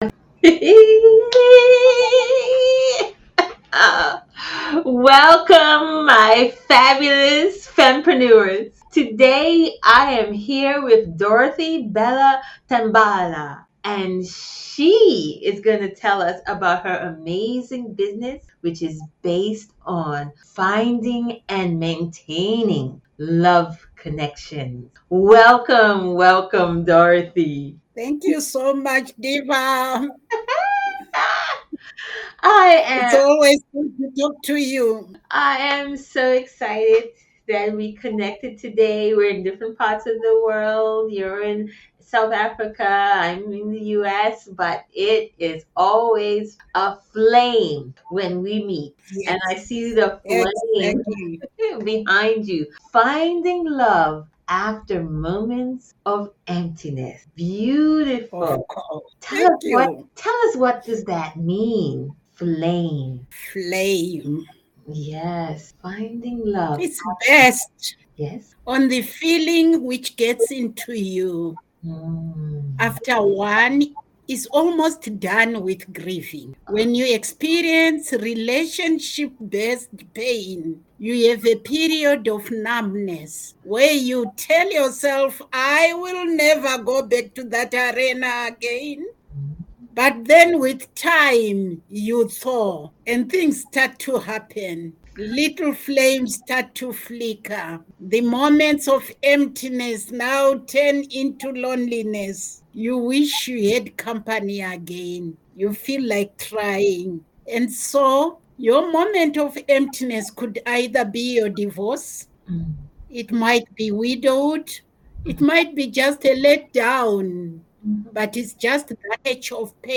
A Podcast Transcription